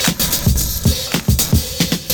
112CYMB01.wav